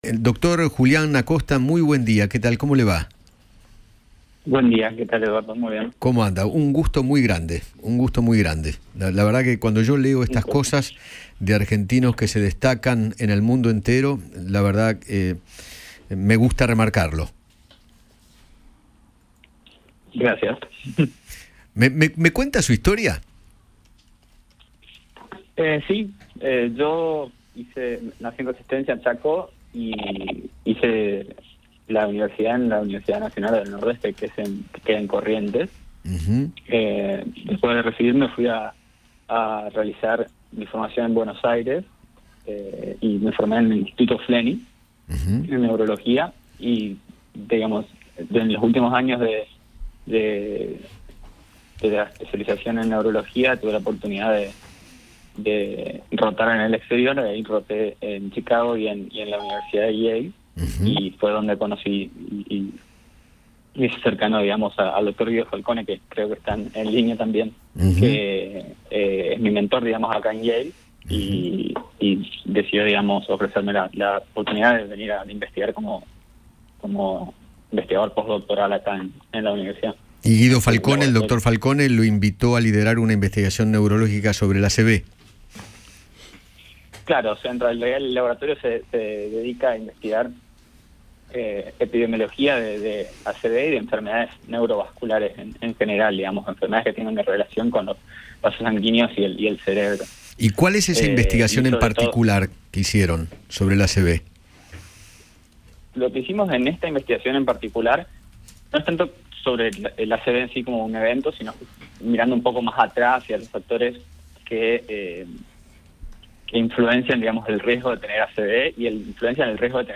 Eduardo Feinmann habló con los doctores